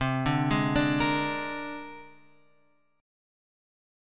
C 7th Suspended 2nd Sharp 5th